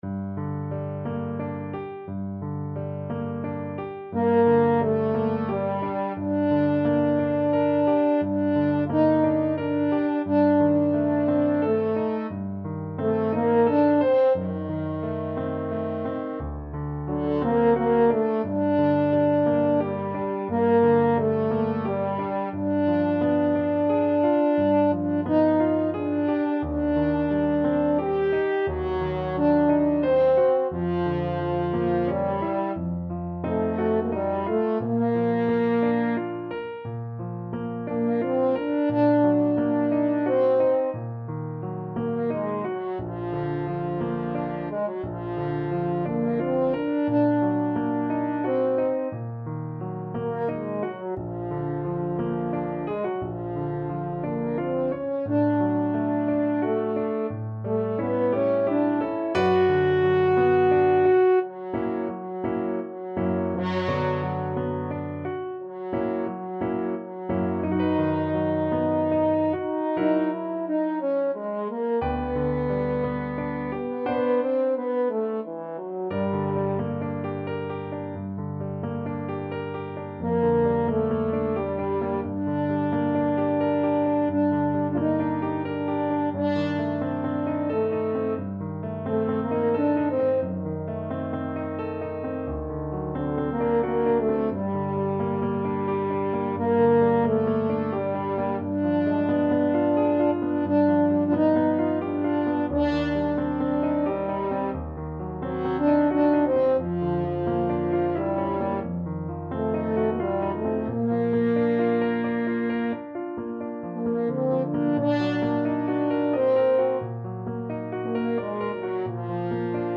French Horn
3/4 (View more 3/4 Music)
G minor (Sounding Pitch) D minor (French Horn in F) (View more G minor Music for French Horn )
~ = 88 Malinconico espressivo
Classical (View more Classical French Horn Music)